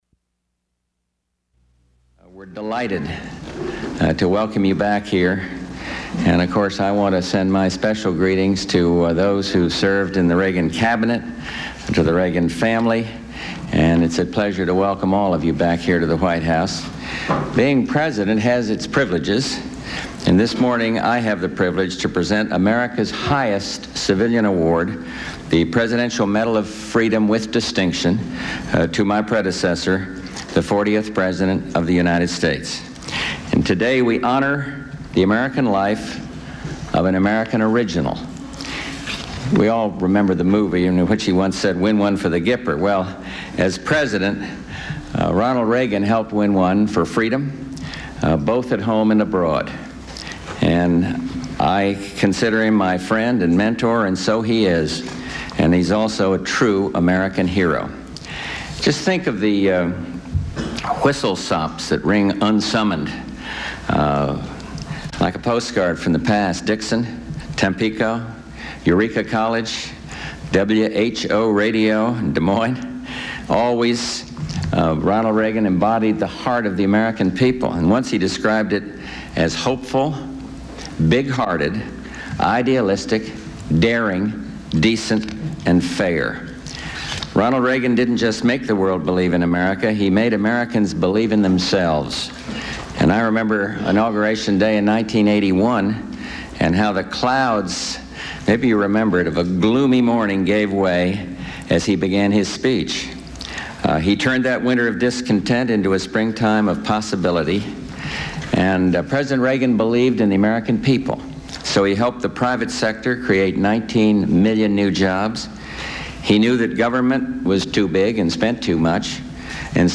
President Bush presents the highest civilian award, the Presidential Medal of Freedom, with Distinction, to his predecessor, the 40th president of the United States, Ronald Reagan. After the citation is read, Reagan responds with praise for Bush.
Medal of Freedom Security, International World politics Material Type Sound recordings Language English Extent 00:16:38 Venue Note Broadcast on CNN, Jan. 13, 1993.